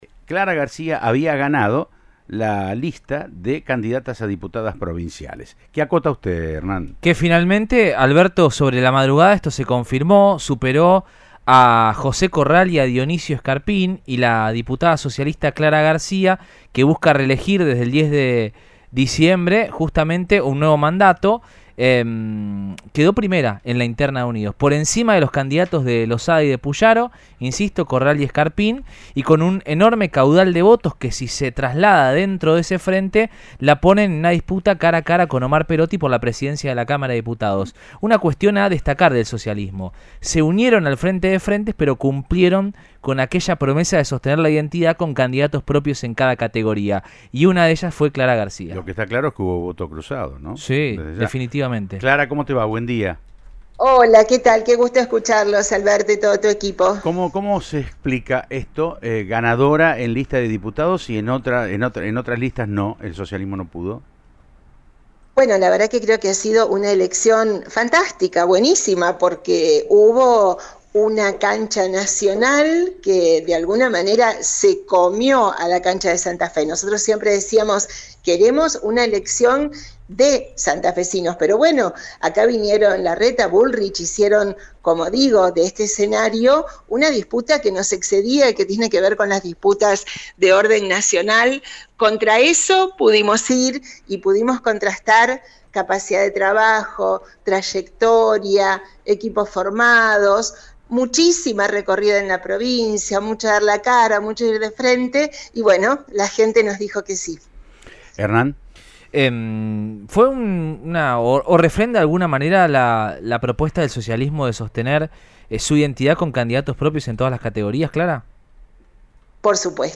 “Vamos a desafiar a Perotti, estamos seguro que le vamos a ganar, lo digo con humildad y seriedad”, le dijo la referente socialista a Siempre Juntos de Cadena 3 Rosario.